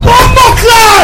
bomboclat kaicenat Meme Sound Effect
This sound is perfect for adding humor, surprise, or dramatic timing to your content.